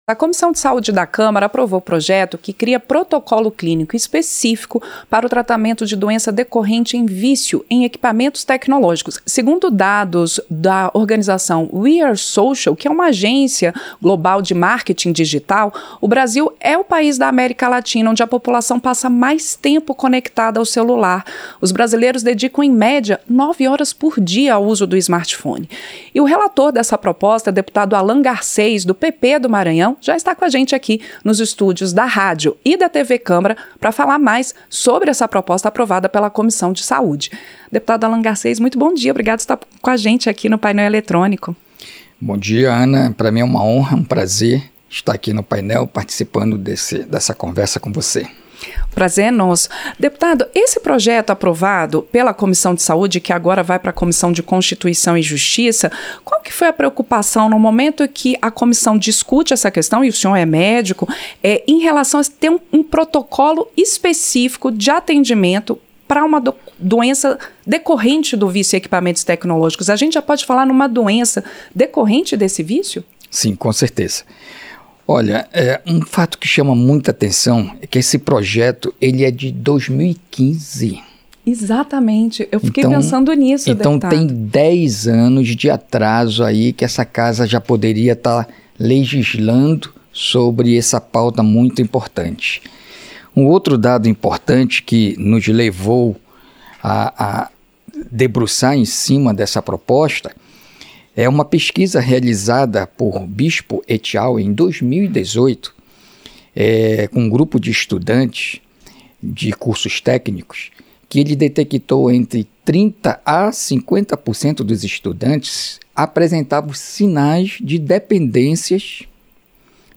Entrevista - Dep. Allan Garcês (PP-MA)